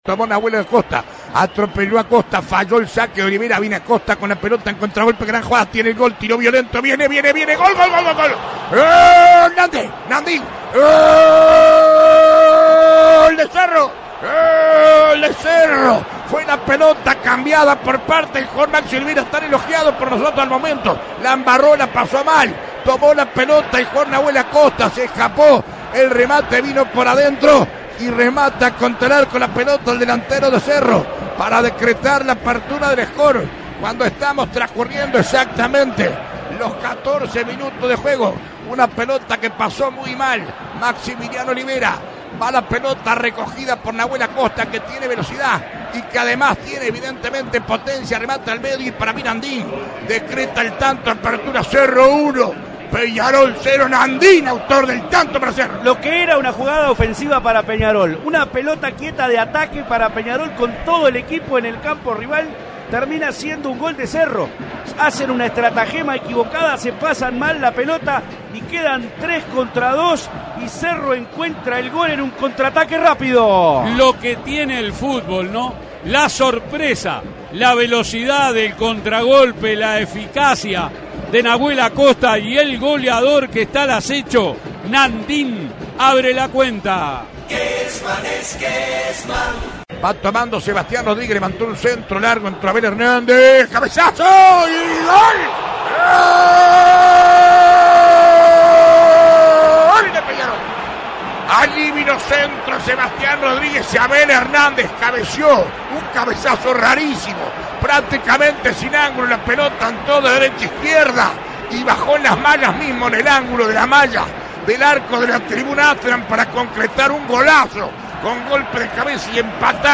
ESCUCHÁ LOS GOLES RELATADOS POR ALBERTO KESMAN